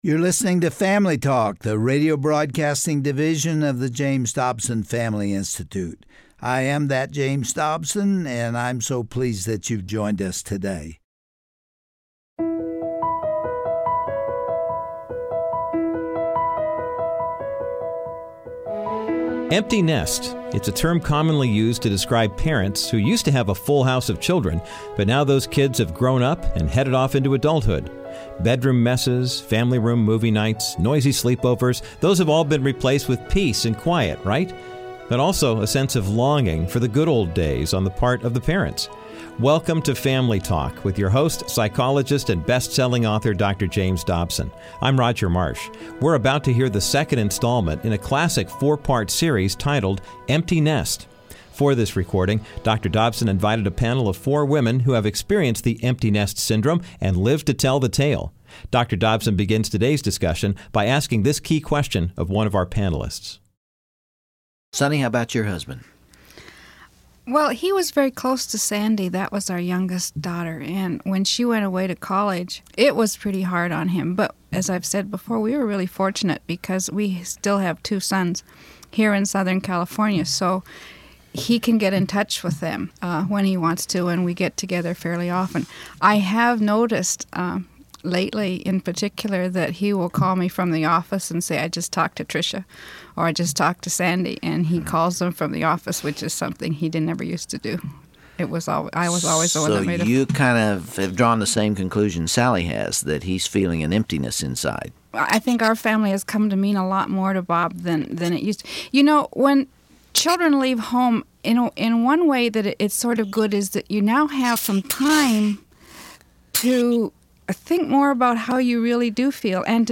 On today’s edition of Family Talk, Dr. James Dobson expands his discussion with a panel of moms on the subject of “empty nesters.” Listen and learn more from the father’s perspective about children leaving home, as well as the mother’s point of view on how “empty nesting” affects the entire family.